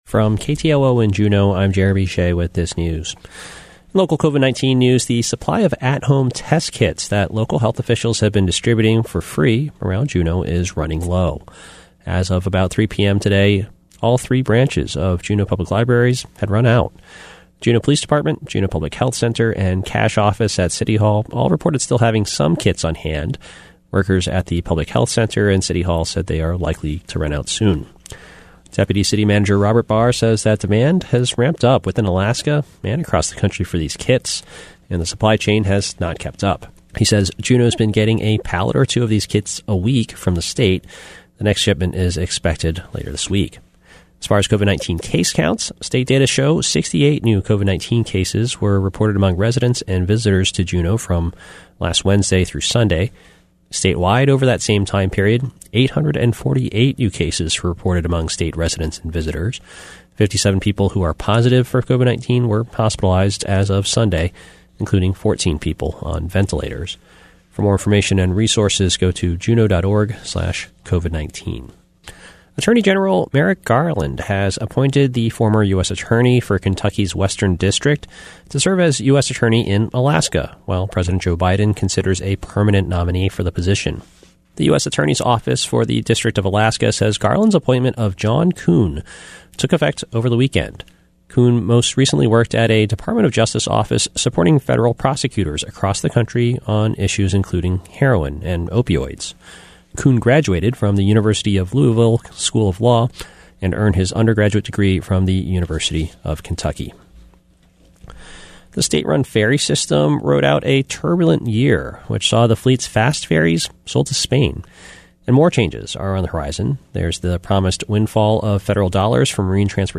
Newscast – Monday, Dec. 27, 2021